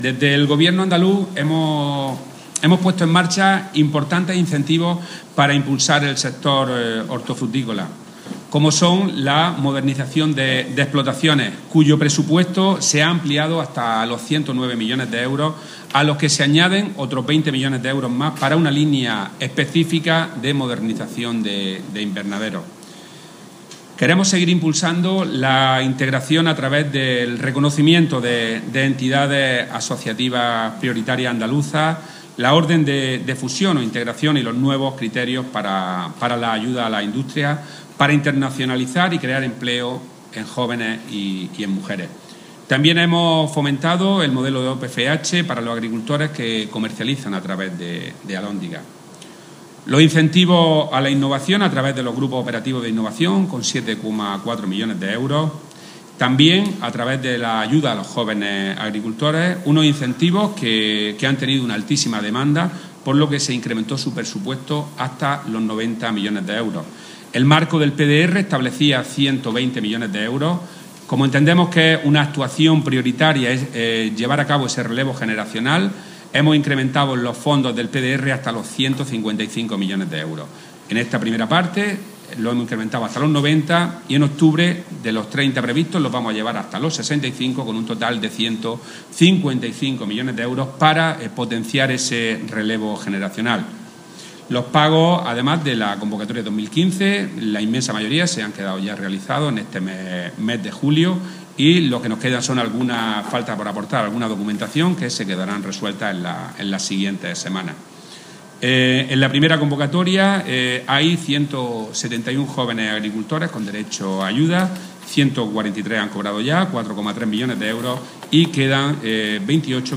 Declaraciones de Rodrigo Sánchez sobre el apoyo de la Junta de Andalucía al sector